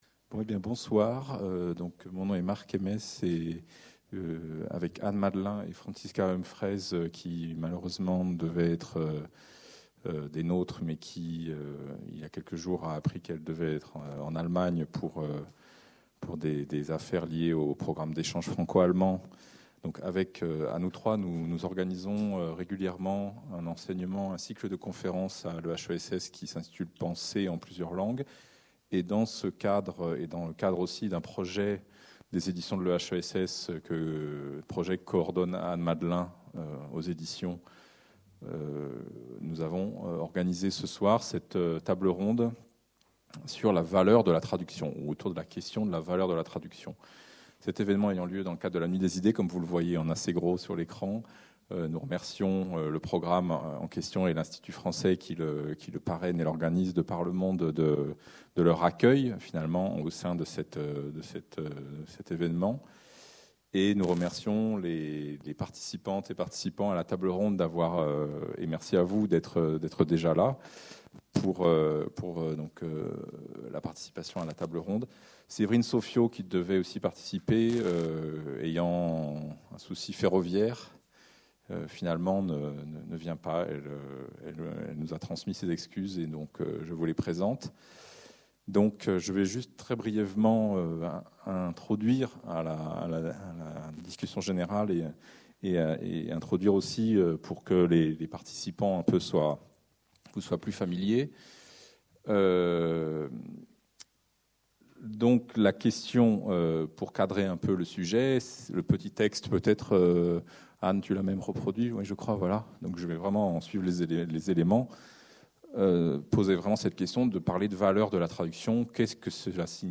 Jeudi 31 janvier 2019 (de 17 h à 20 h, amphithéâtre François Furet, 105 bd Raspail 75006 Paris)